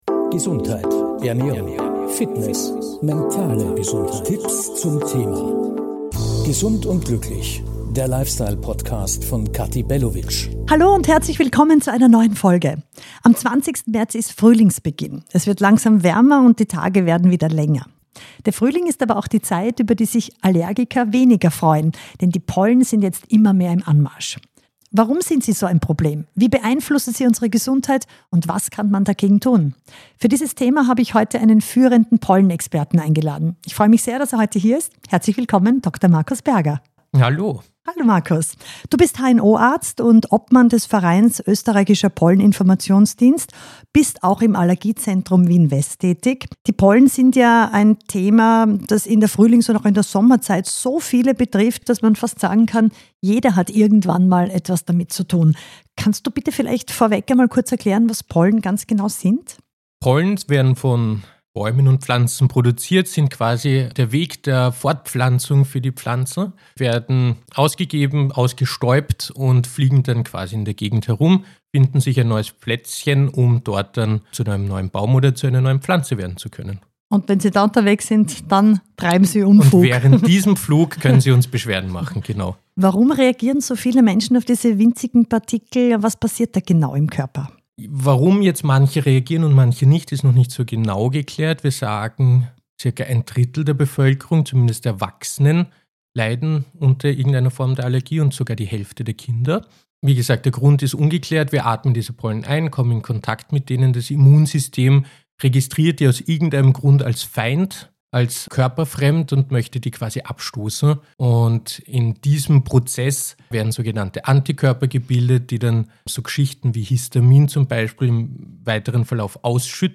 In dieser Podcastfolge spreche ich mit dem HNO Arzt und Pollenexperten